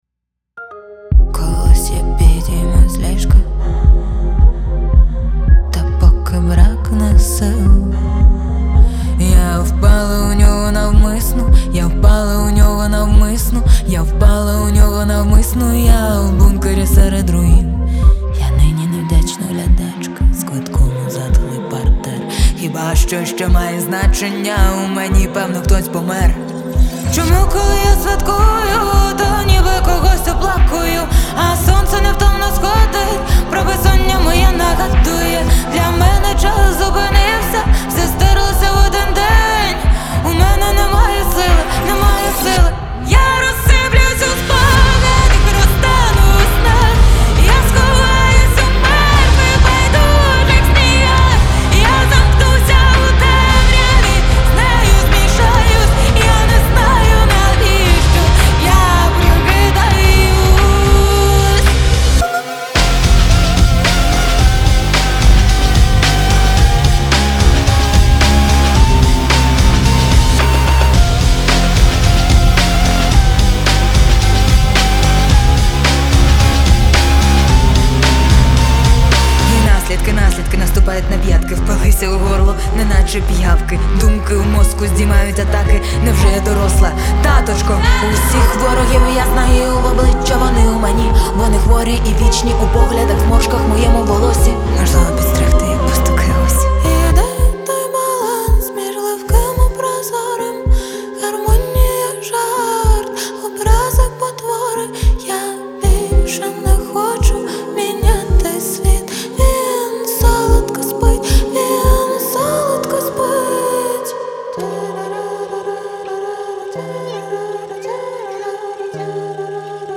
• Жанр: Pop, Indie